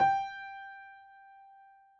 piano_067.wav